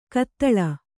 ♪ kattaḷa